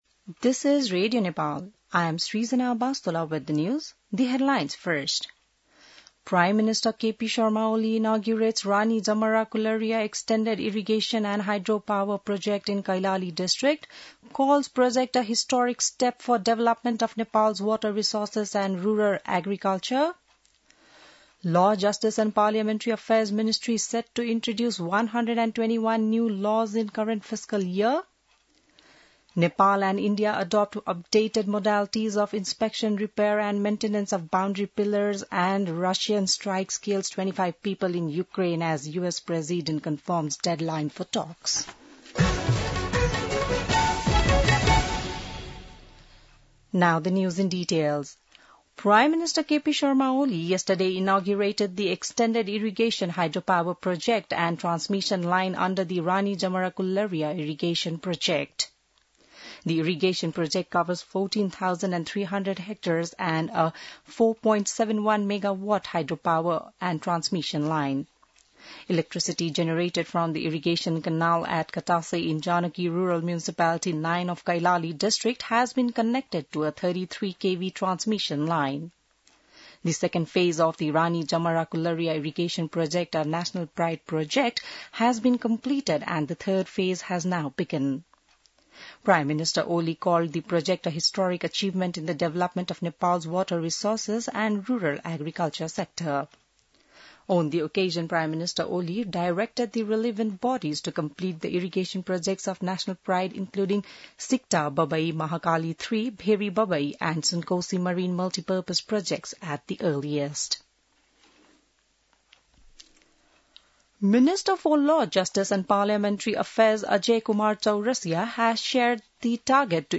बिहान ८ बजेको अङ्ग्रेजी समाचार : १४ साउन , २०८२